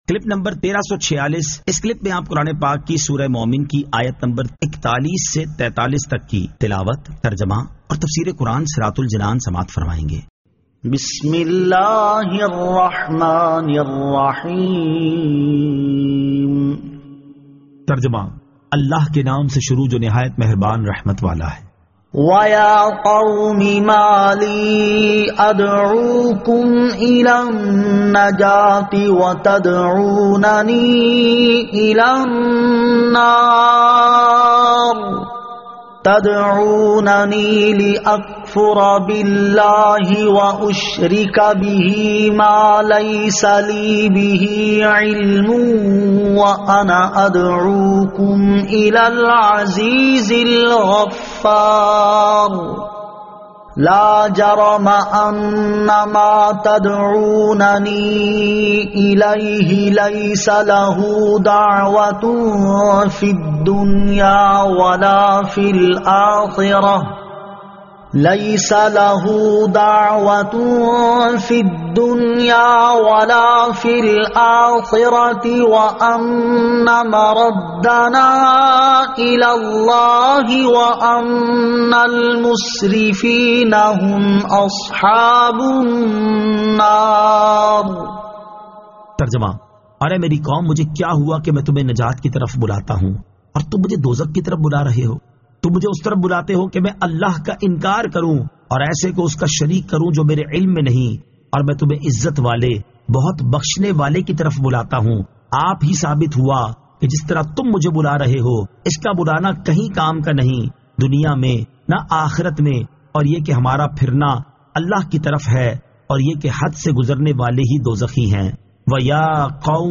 Surah Al-Mu'min 41 To 43 Tilawat , Tarjama , Tafseer